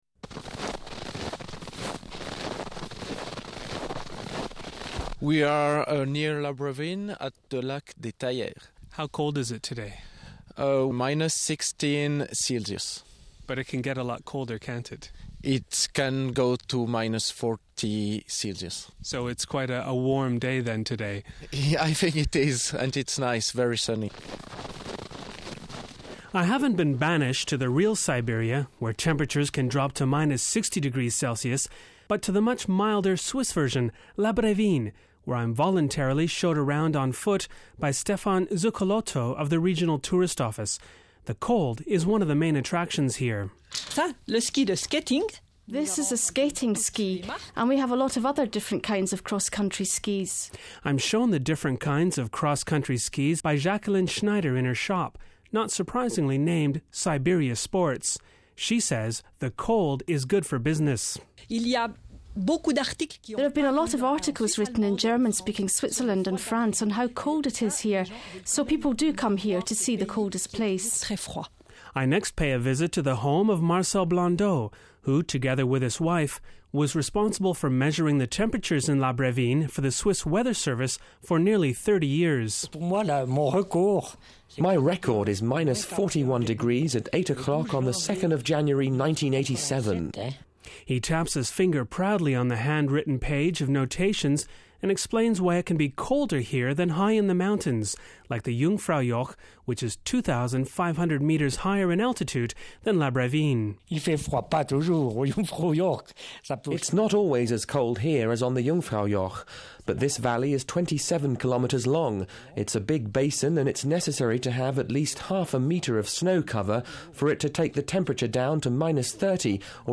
There’s been plenty of fresh snowfall and below freezing temperatures in Switzerland this winter. But there’s one country of the Swiss countryside that is colder than anywhere else, La Brévine (BREH-VIN-E), a village in the Jura hills.